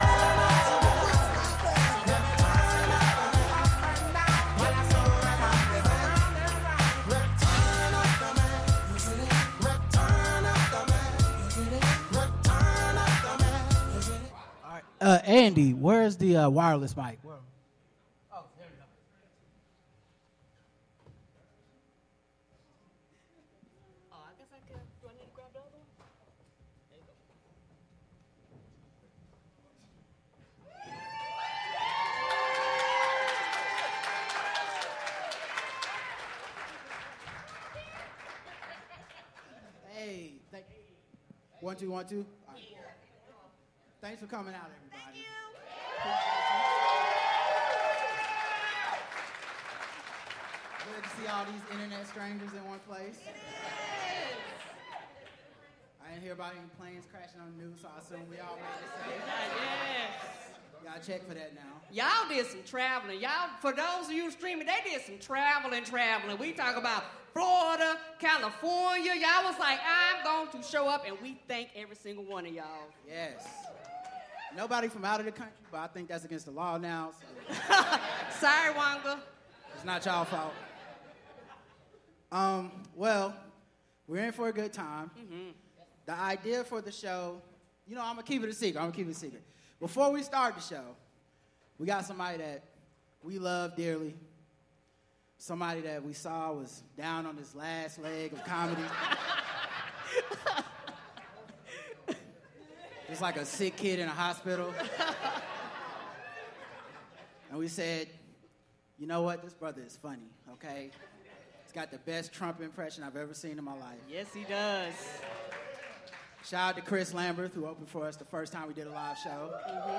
The Black Guy Who Tips Live 2025
LiveShow2025.mp3